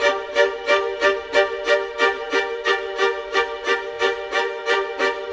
Rock-Pop 07 Violins 01.wav